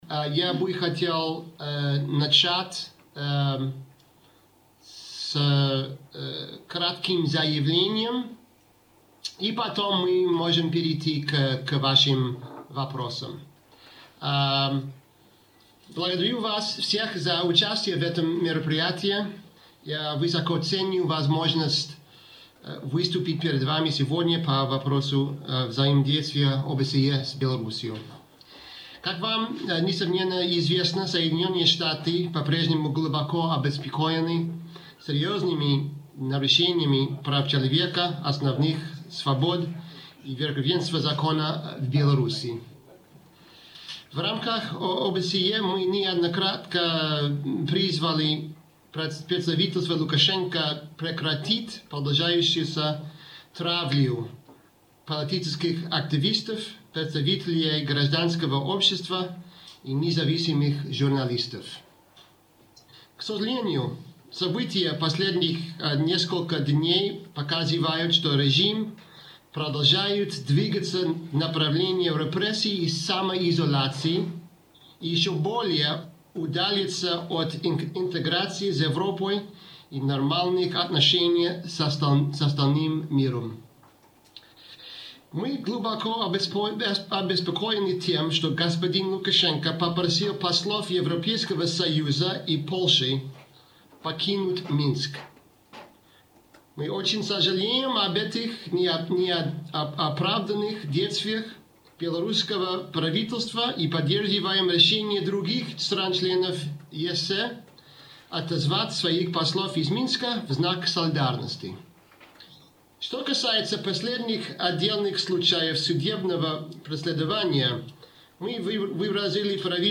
Прэс-канфэрэнцыя амбасадара ЗША пры АБСЭ Іэна Кэлі